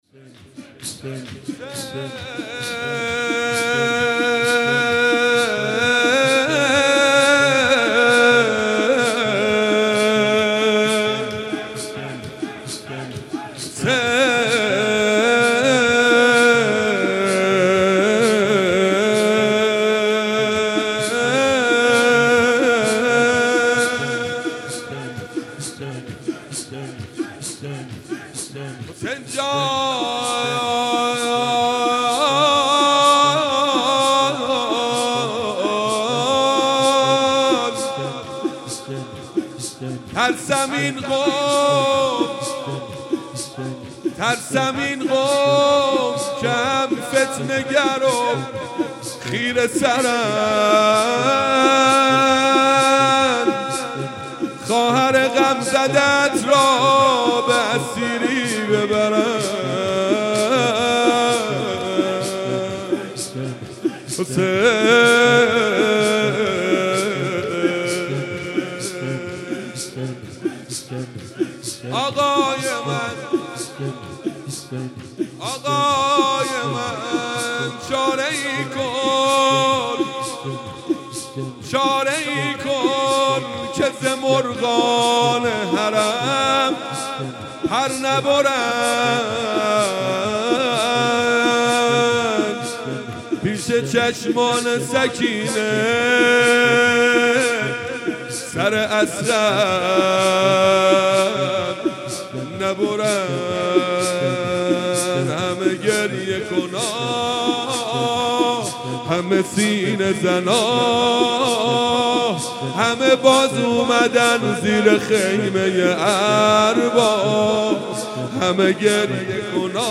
شب اول محرم 97 - شور - ترسم این قوم که هم فتنه گر و